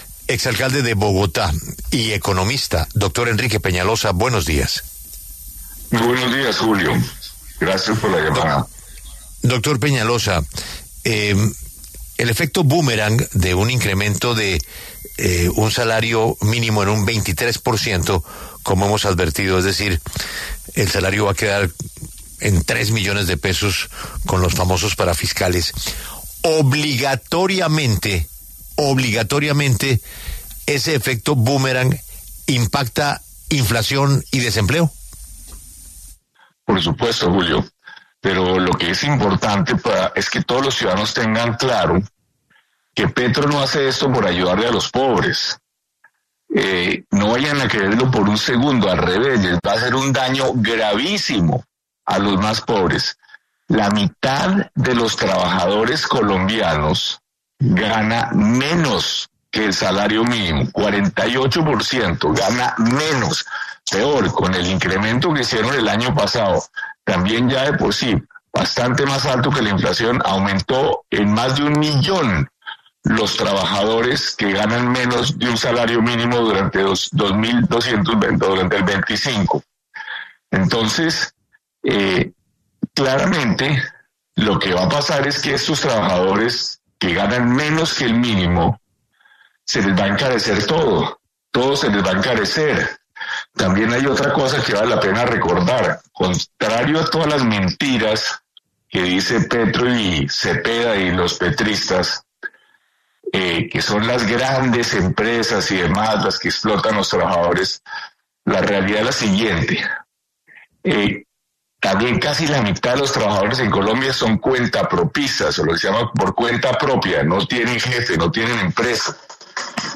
En los micrófonos de La W, con Julio Sánchez Cristo, habló el exalcalde de Bogotá Enrique Peñalosa, quien se refirió a la noticia de la semana: el aumento en un 23,7% del salario mínimo que quedó en 2′000.000 de pesos, incluido el auxilio de transporte.